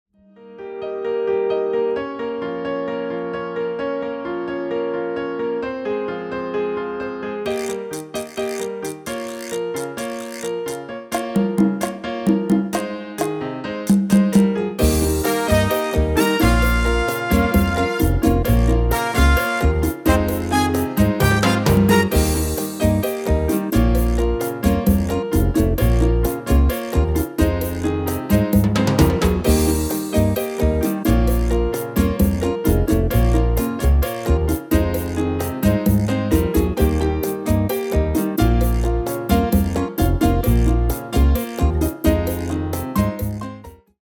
MP3 backing track arranged in the style of:
Genre: Actuele hitlijsten
Key: C
File type: 44.1KHz, 16bit, Stereo
Demo's played are recordings from our digital arrangements.